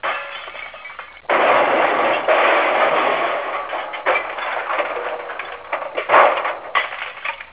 glass.wav